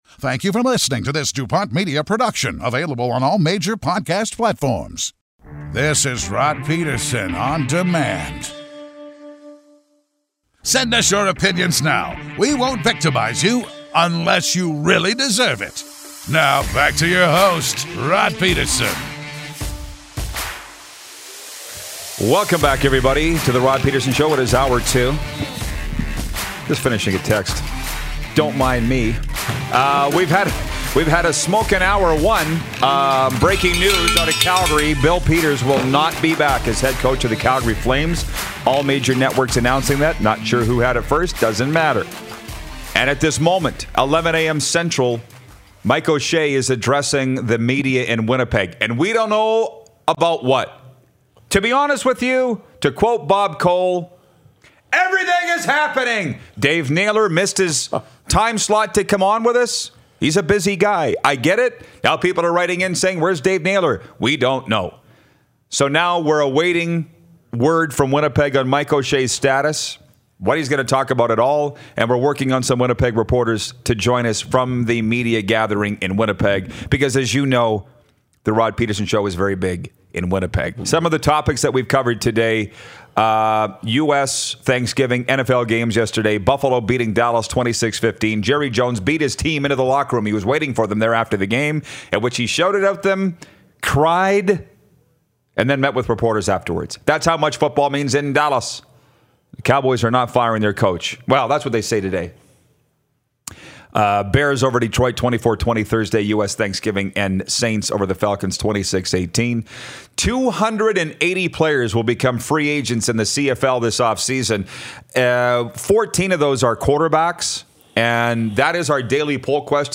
Ignore the snow and slippery roads, grab some coffee and warm up with some ? sports talk!
NHL All-Star John Scott checks in to see us out!